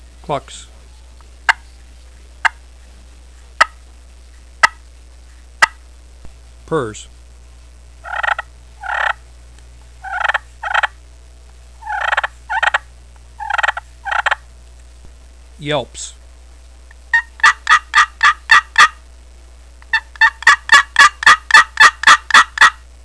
Listen to 23 seconds of clucks, purrs, and yelps
• Cluck, purr, tree yelp and soft yelp on the slate part of the call with the striker. Yelp and cutt with plenty of volume by stroking the lip of the box against the rectangular piece of slate.
• Makes excellent raspy and smooth yelps, clucks, purrs, whines, and cutts at any volume
ccscratchall23.wav